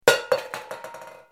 دانلود آهنگ تصادف 3 از افکت صوتی حمل و نقل
دانلود صدای تصادف 3 از ساعد نیوز با لینک مستقیم و کیفیت بالا
جلوه های صوتی